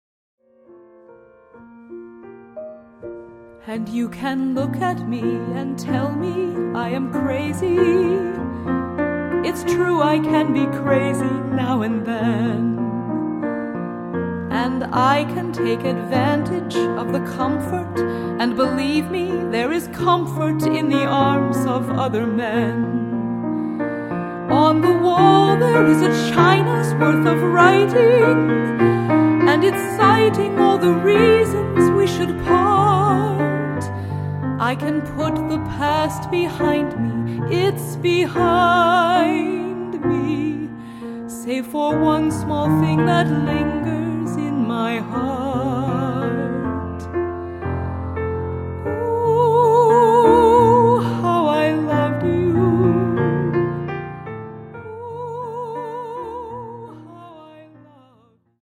In-studio recordings: